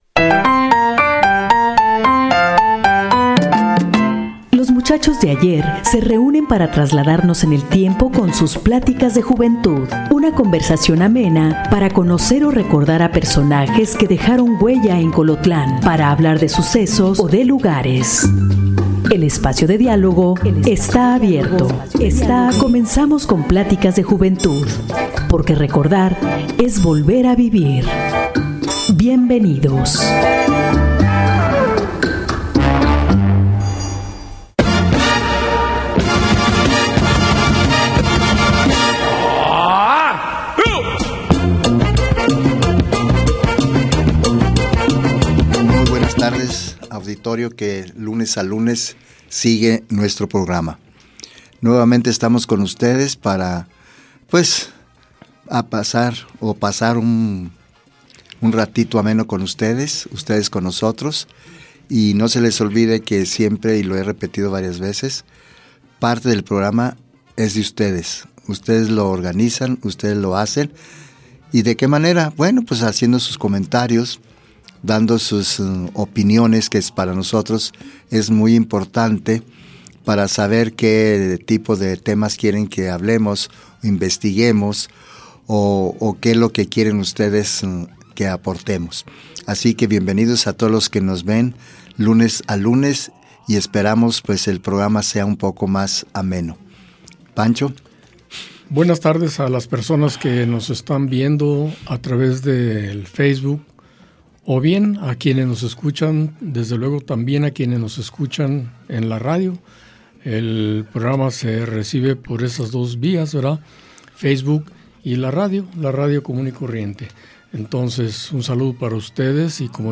Rescatar la riqueza oral de los pueblos para trasmitirla a nuevas generaciones a través de una plática amena e informal, es cometido principal del programa Pláticas de juventud, donde se escucha la voz de la experiencia y se reviven recuerdos de diferentes generaciones de hombres colotlenses. La vida del pueblo narrada por voces masculinas.